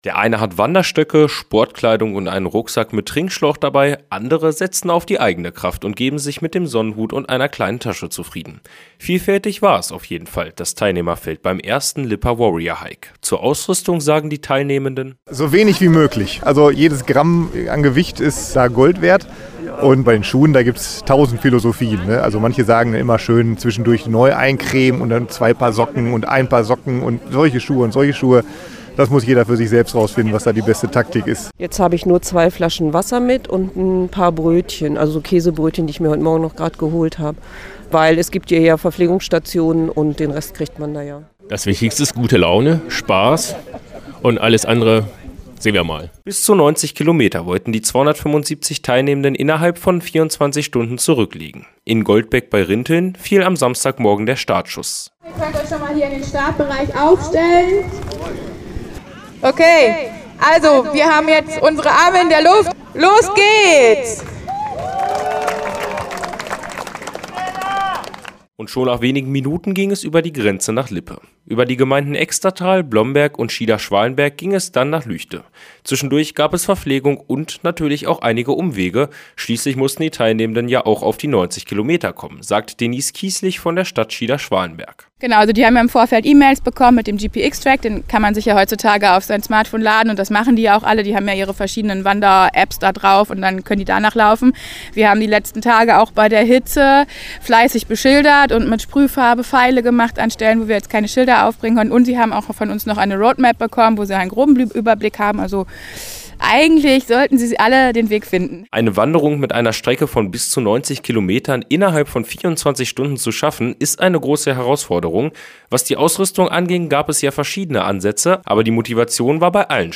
Aktuelle Lokalbeiträge Region Weserbergland: WARRIOR HIKE LIPPE Play Episode Pause Episode Mute/Unmute Episode Rewind 10 Seconds 1x Fast Forward 30 seconds 00:00 / Download file | Play in new window Am vergangenen Wochenende fand in Lippe der erste Warrior-Hike statt. In bis zu 24 Stunden legten knapp 300 Wanderer dort eine Distanz von bis zu 90 Kilometern zurück.